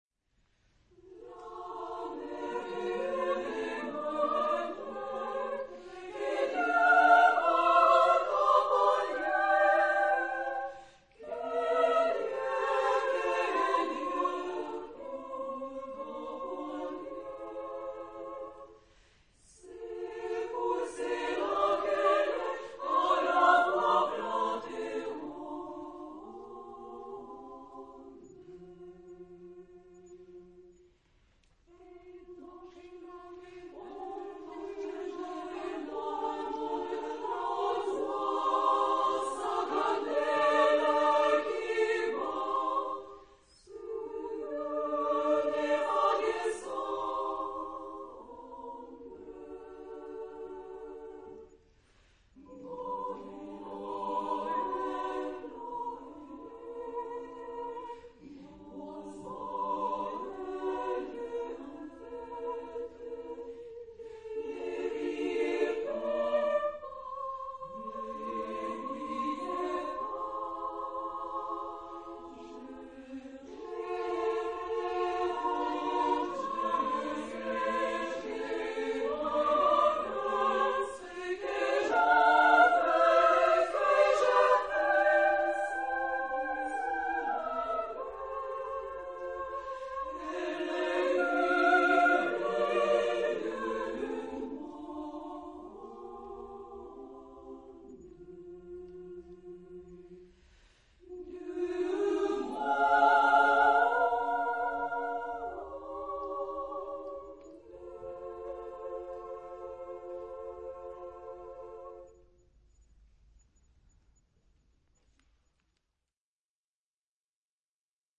Genre-Style-Forme : Enfants ; Profane
Type de choeur : SAA OU SSA  (3 voix égale(s) d'enfants OU égales de femmes )
Tonalité : fa majeur
Réf. discographique : Florilège Vocal de Tours, 2005